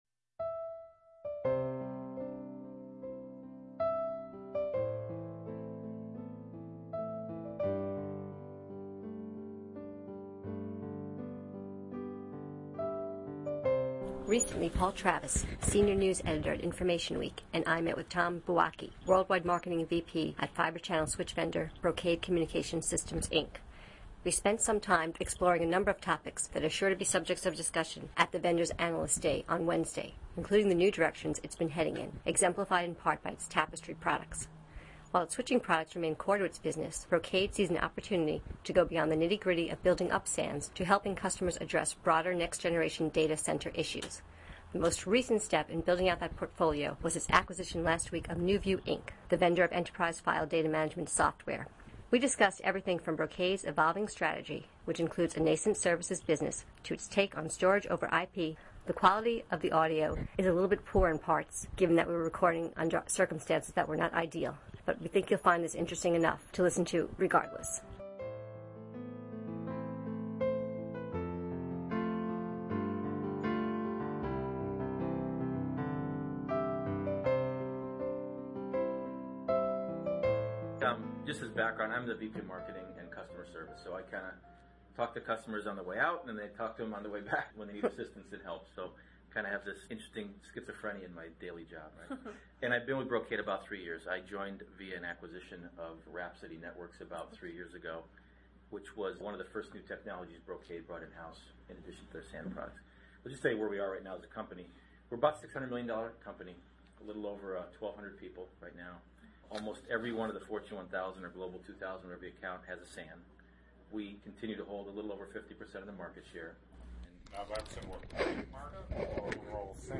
During our interview we discussed everything from Brocade???s evolving strategy, which also includes a nascent services business.